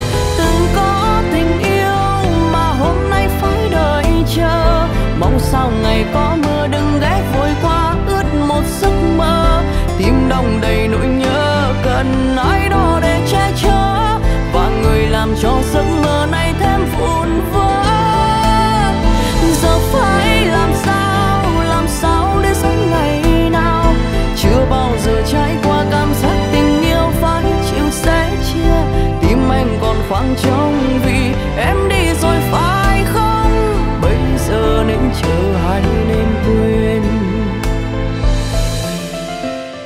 Nhạc chuông 6 lượt xem 08/03/2026